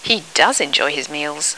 In inglese vengono scritte in corsivo quelle parole sulle quali, nel discorso, viene posto un accento enfatico (si tratta spesso di parole solitamente non accentate: verbi ausiliari, pronomi, ecc.):